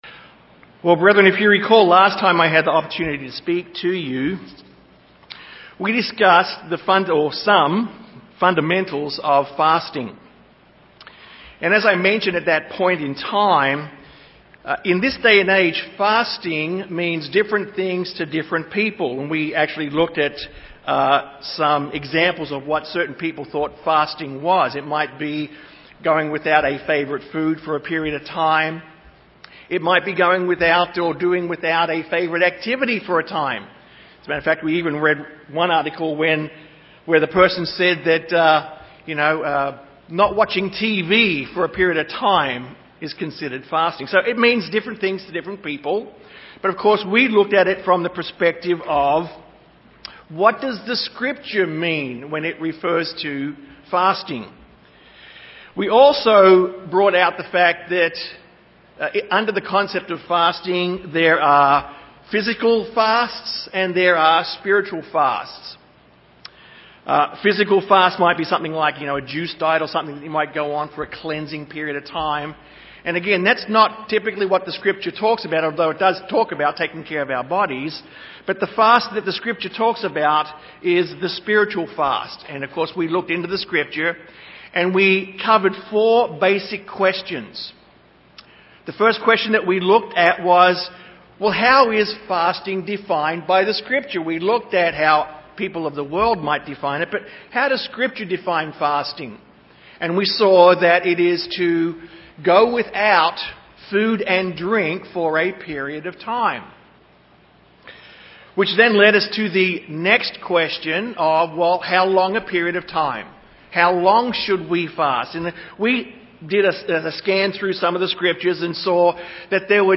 Whether God will accept your fast or not depends on your purpose for fasting. This sermon addresses the purpose for fasting and shows us how our fasting can be both profitable and accepted by God.